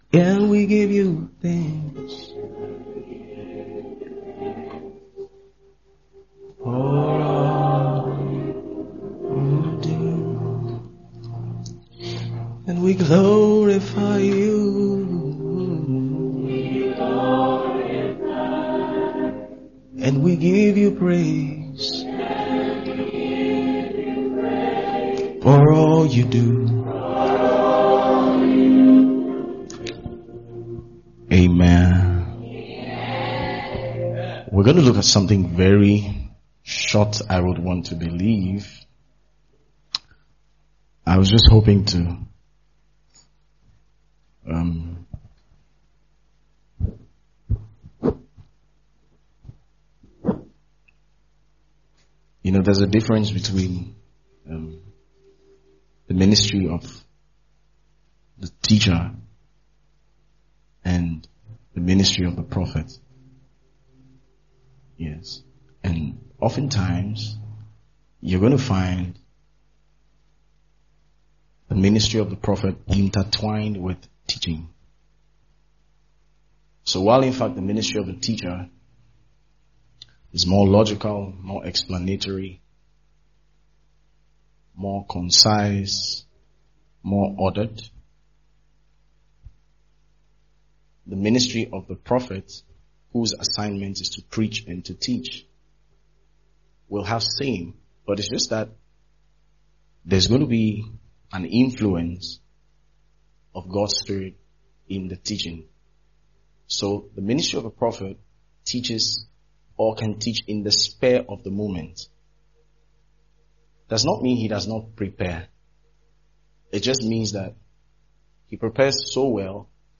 A 3-part sermon series on Take Heed To Thy Spirit